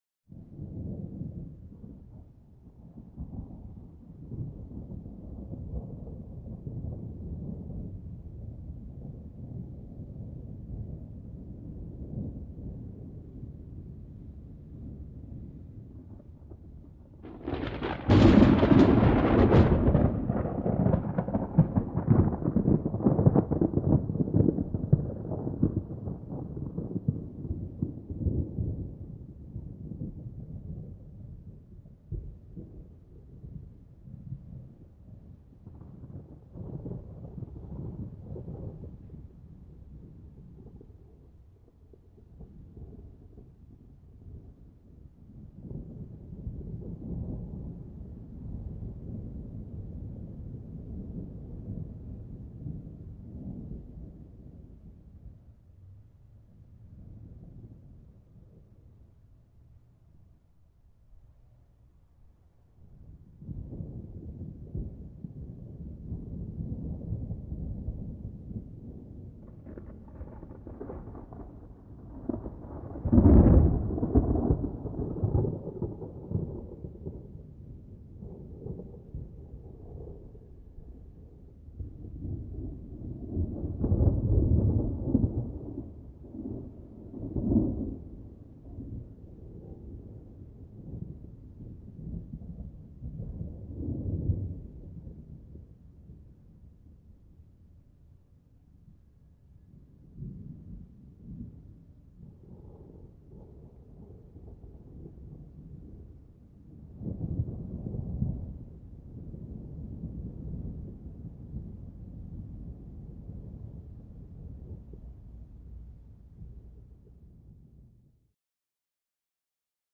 Thunder; Dry, Rumbles And Cracks In The Distance Without Rain.